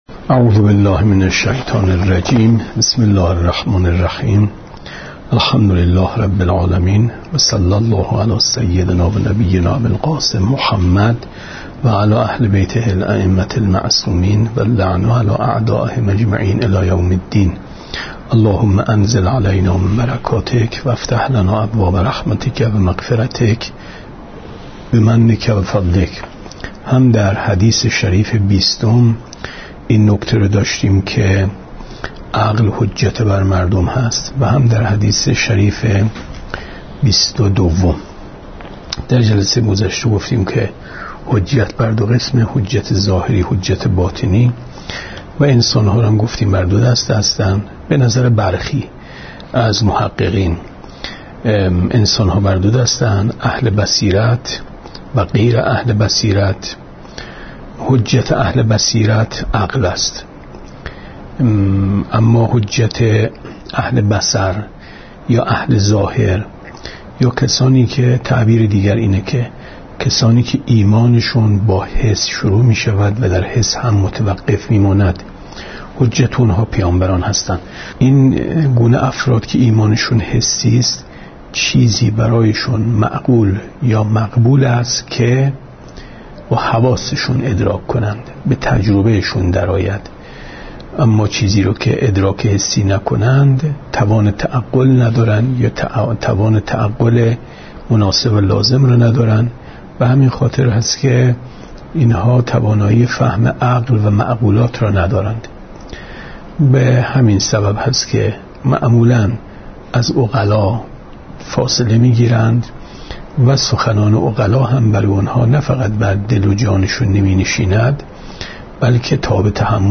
گفتارهای ماه مبارک رمضان 1436 ـ جلسه بیست و یکم ـ 25/ 4/ 94 ـ شب سی‌ام ماه رمضان